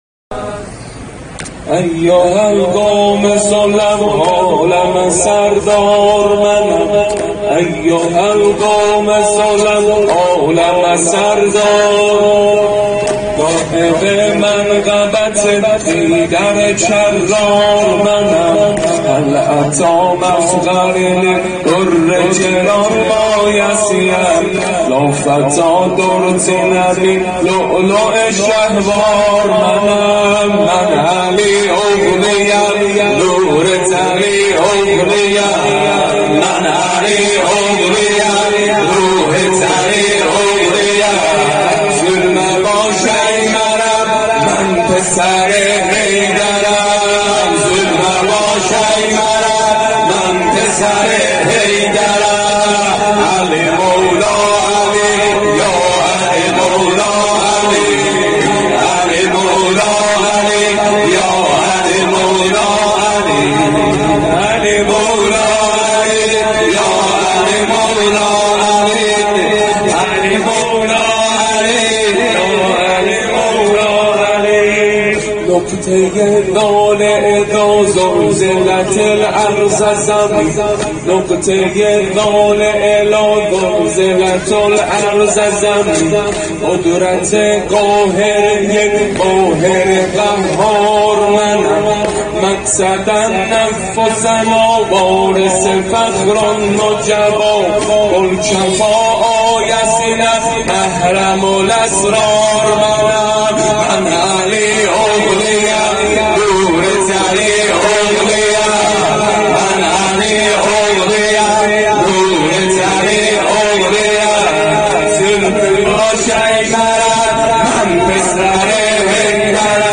مداحی ترکی
نوحه ترکی